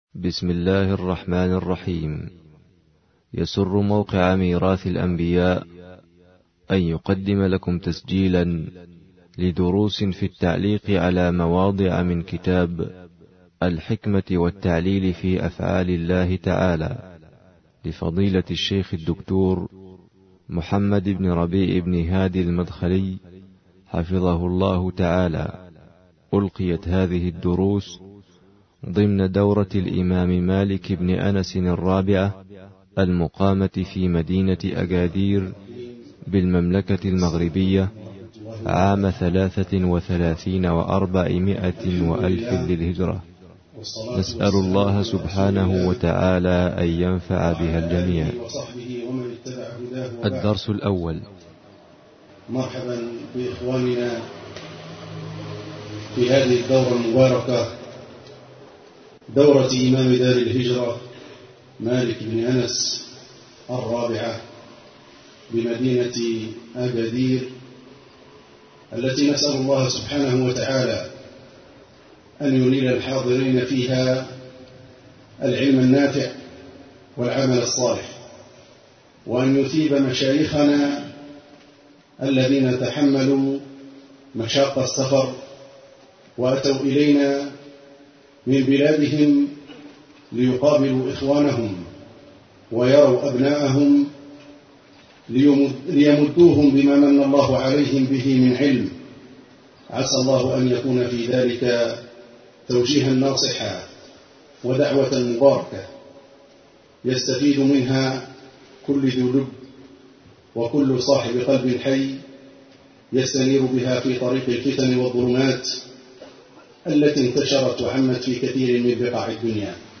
ضمن فعاليات دورة إمام دار الهجرة مالك بن أنس العلمية بالمغرب
الدرس 1